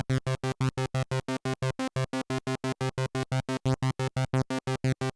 Here’s mine… doesn’t sound like a tuning issue to me, just two sawteeth fighting it out.